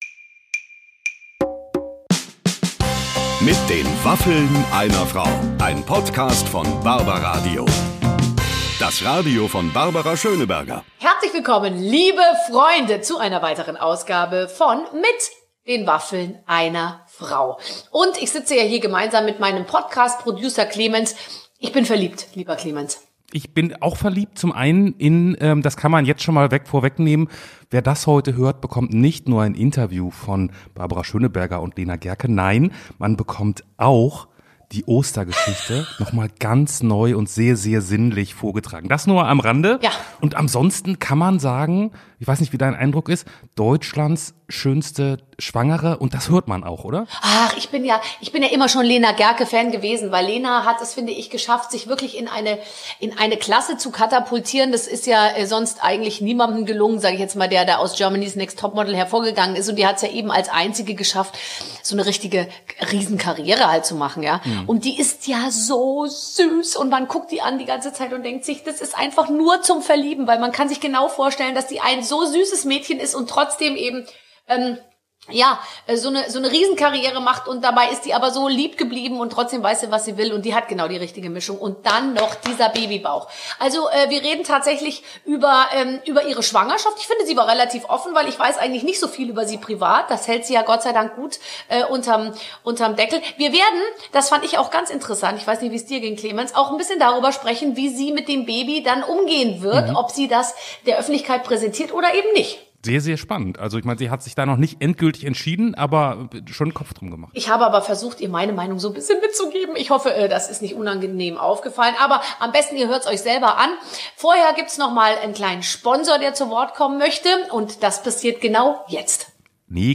Lena Gercke ist vor kurzem umgezogen. Im Interview mit Barbara Schöneberger erzählt sie, wie ihr Umzug verlief und erklärt, warum es so befreiend sein kann, sich von Dingen zu trennen.